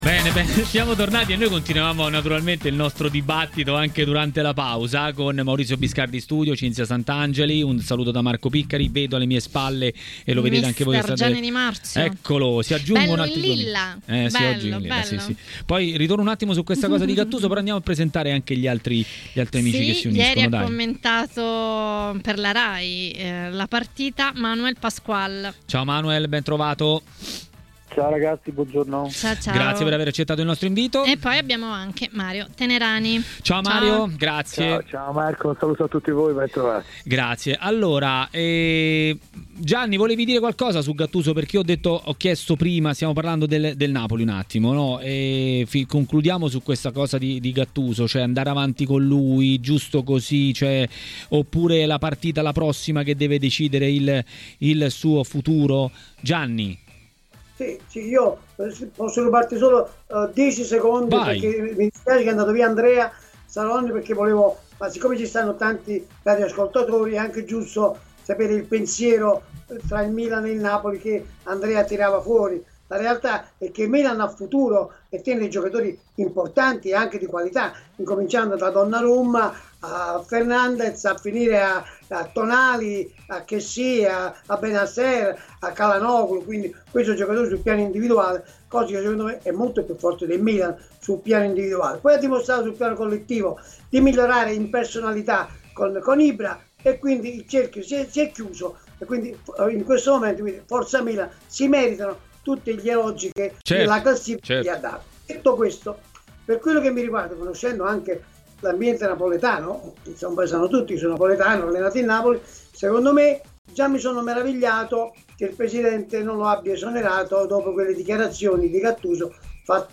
A parlare dell'uscita dalla Coppa Italia del Napoli a TMW Radio, durante Maracanà, è stato mister Gianni Di Marzio.
Le Interviste